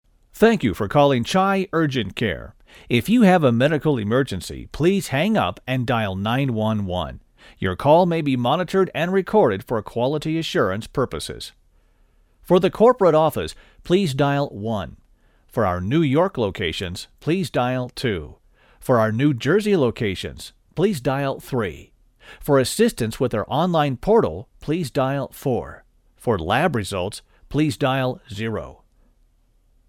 Auto Attendant